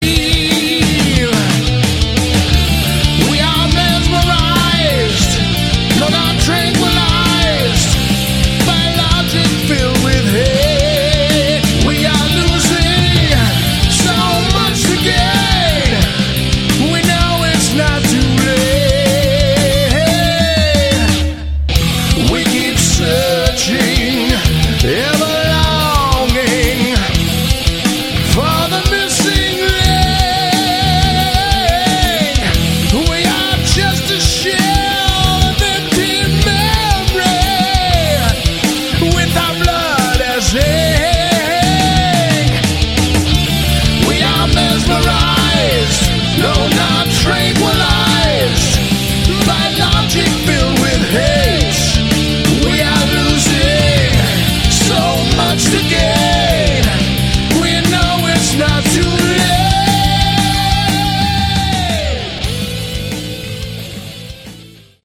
Category: Hard Rock
guitars
vocals